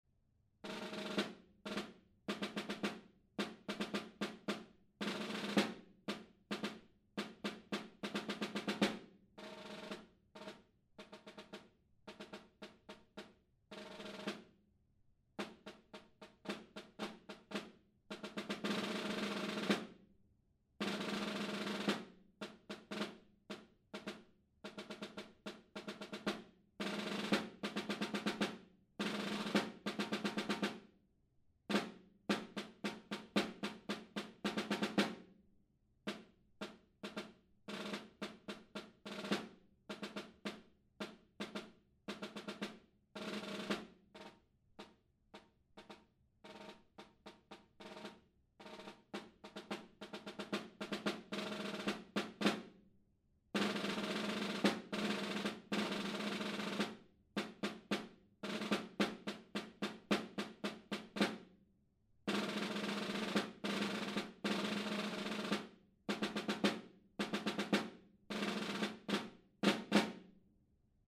Encore 2026 Snare Drum Solos
Demo